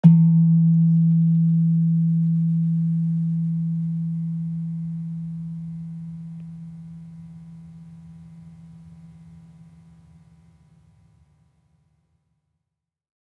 Gender-4-E2-f.wav